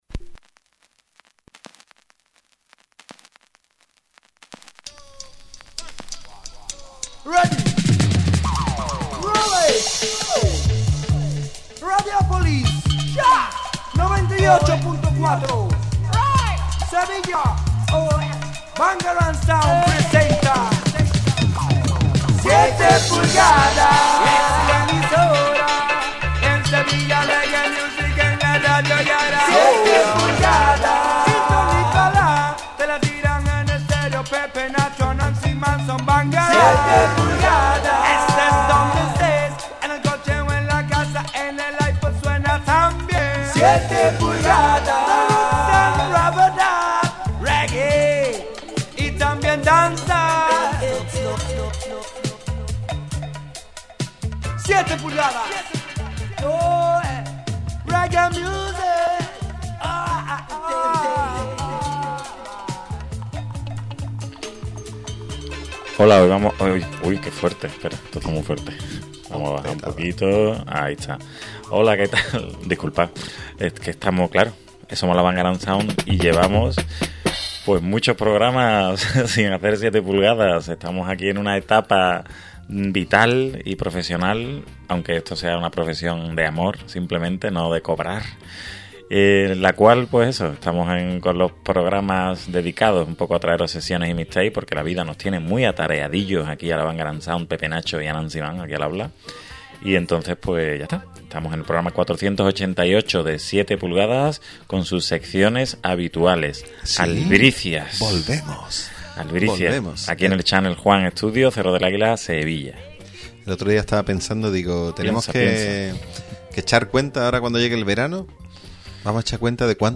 Ya podéis escuchar el programa 488 de 7PULGADAS, emitido este pasado viernes 25 de mayo. Presentado y dirigido por la Bangarang Sound y grabado en Channel Juan Studio.